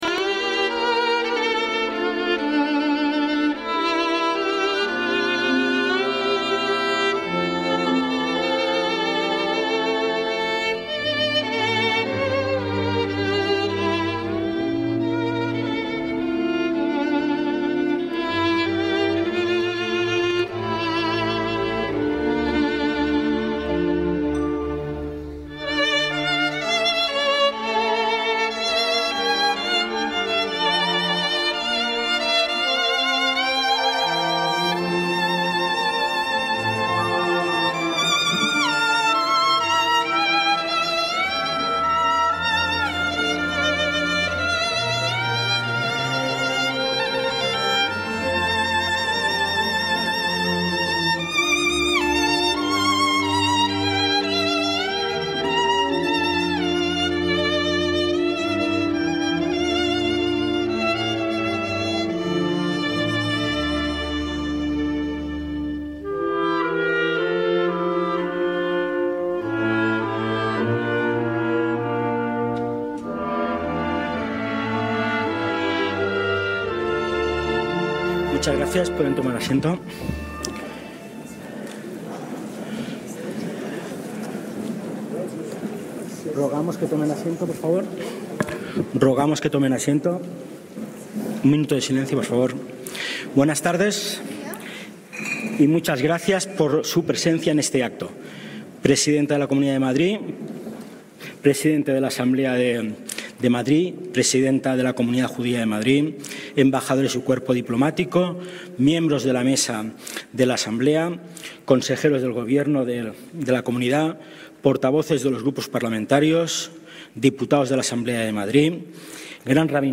ACTOS EN DIRECTO - El 28 de enero de 2025 tuvo lugar en la Asamblea de Madrid el Acto en Recuerdo del Holocausto organizado conjuntamente por esta organización y la Comunidad Judía de Madrid, con la colaboración del Centro Sefarad Israel y Yad Vashem España.